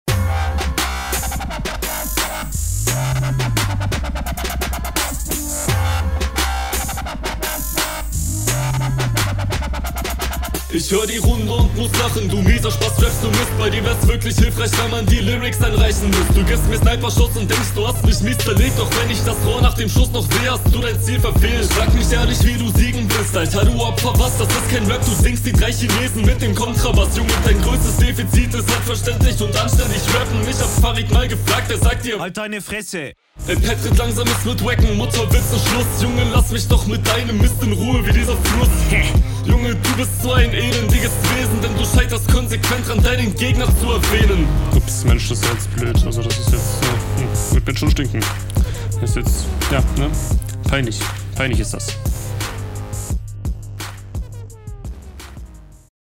Also Mixxing/Mastering top. Alles verständlich.
Jo rappst bisschen unroutinierter, dafür aber weitaus verständlicher als dein Gegner.